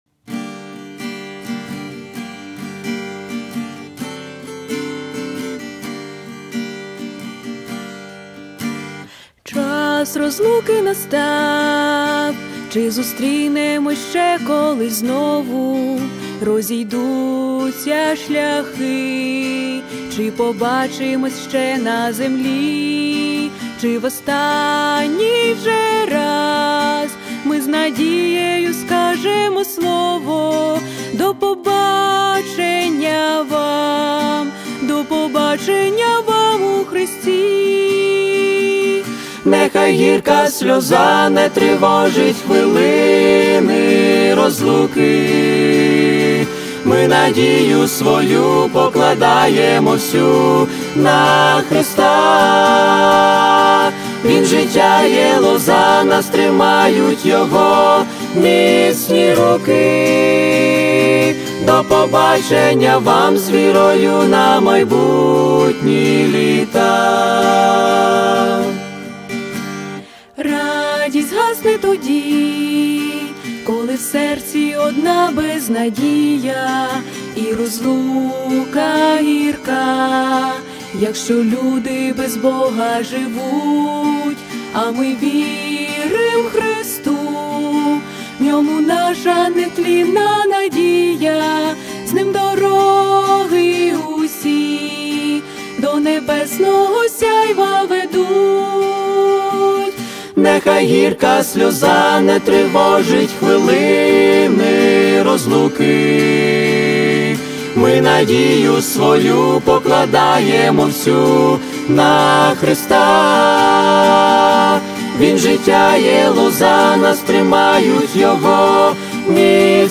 832 просмотра 608 прослушиваний 296 скачиваний BPM: 130